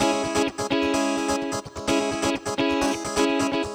VEH3 Electric Guitar Kit 1 128BPM
VEH3 Electric Guitar Kit 1 - 10 C# min.wav